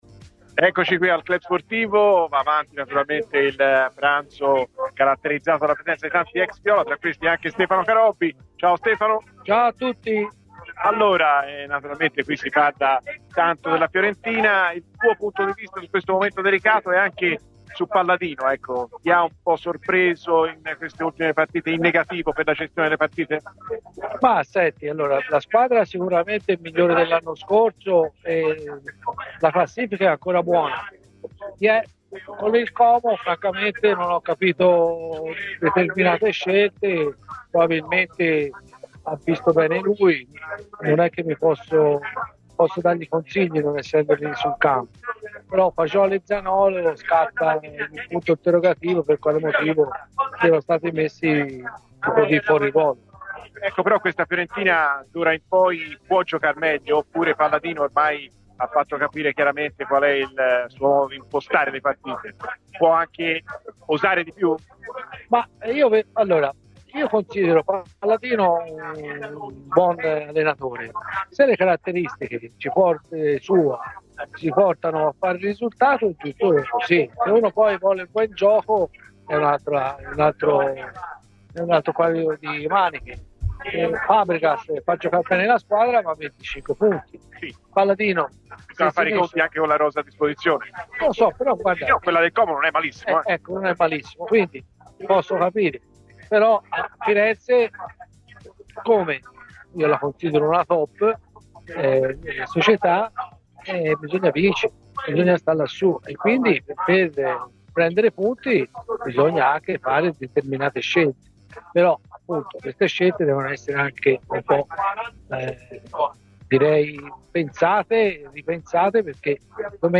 Stefano Carobbi, ex difensore tra le altre di Fiorentina e Milan, è intervenuto ai microfoni di Radio FirenzeViola a margine del pranzo tra vecchie glorie: "La squadra sicuramente è migliore dell'anno scorso e la classifica è ancora buona. Contro il Como non ho capito determinate scelte di Palladino, ad esempio il ruolo in cui sono stati impiegati Fagioli e Zaniolo".